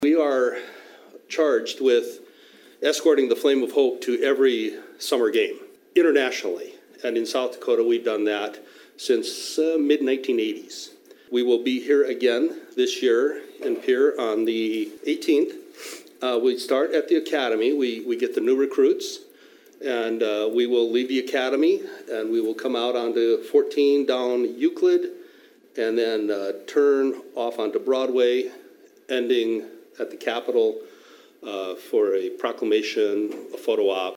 spoke before the Pierre City Commission on Tuesday.